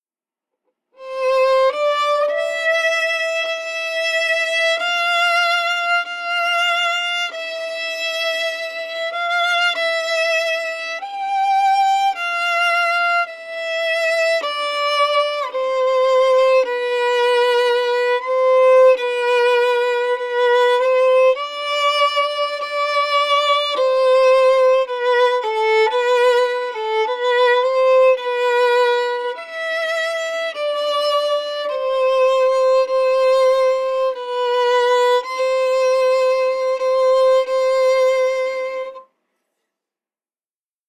played slowly/soberly on the violin